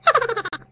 laugh001
boo haha hehe laugh laugher mario64 sound effect free sound royalty free Funny